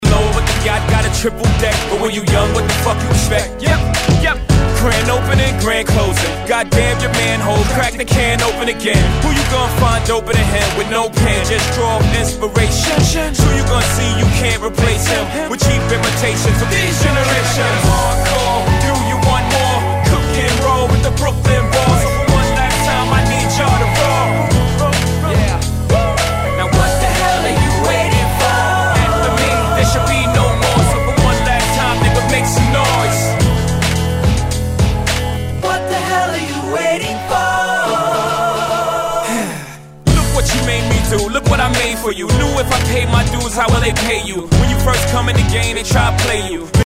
Tag       HIP HOP HIP HOP